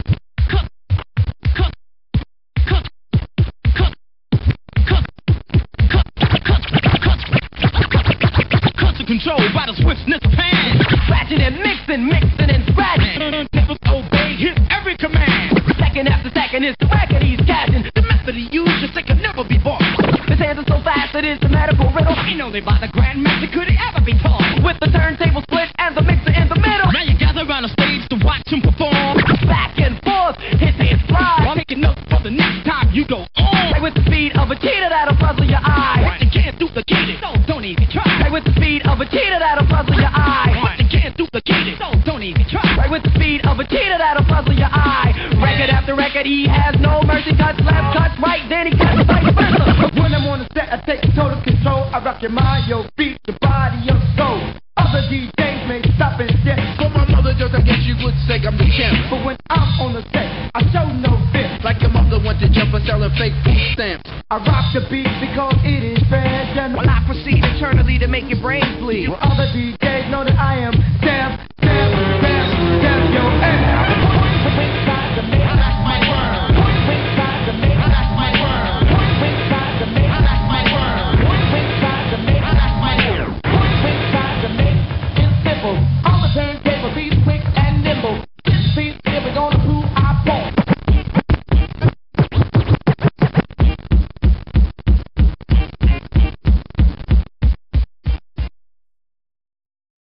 two turntables: